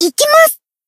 BA_V_Kokona_Battle_Shout_2.ogg